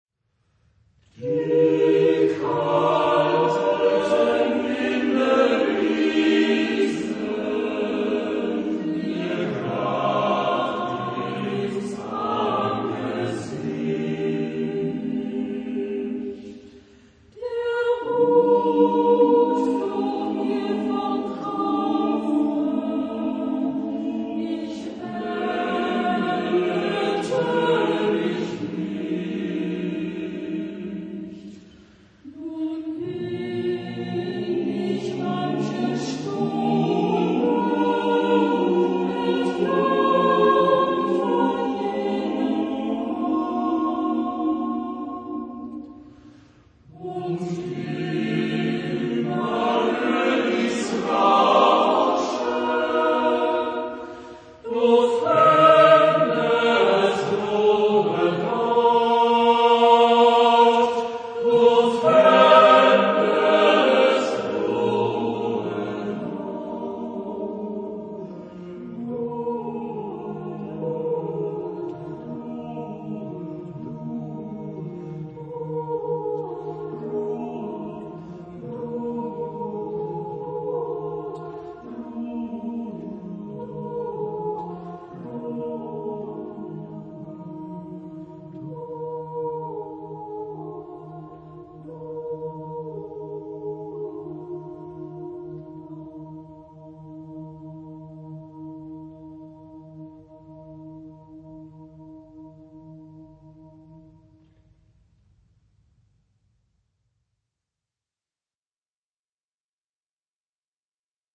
Género/Estilo/Forma: Canción ; Folklore ; Profano
Tipo de formación coral: SAATB  (5 voces Coro mixto )
Tonalidad : mi bemol mayor